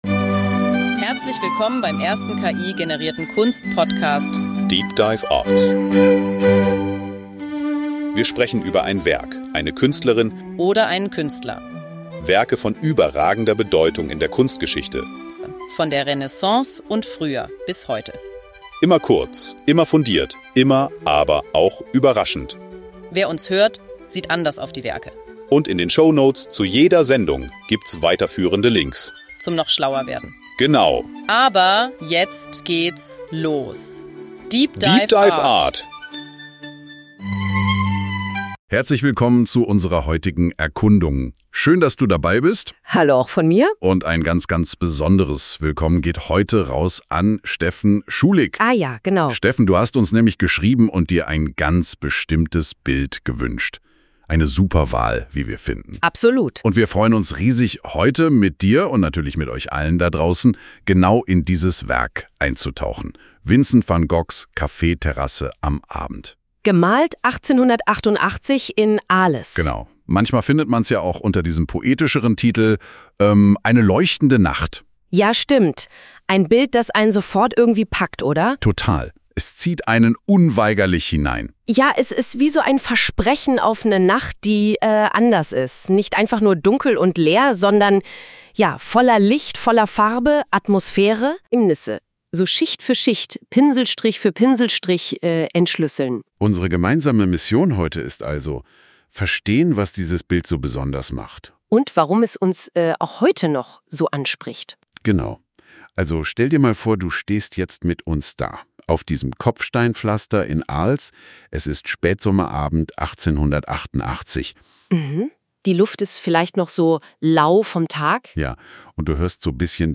Die Diskussion umfasst die technische Umsetzung, die spirituellen Dimensionen und die persönlichen Reflexionen der Zuhörer über die Bedeutung des Werkes. DEEP DIVE ART ist der erste voll-ki-generierte Kunst-Podcast.
Die beiden Hosts, die Musik, das Episodenfoto, alles.